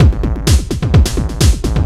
DS 128-BPM B7.wav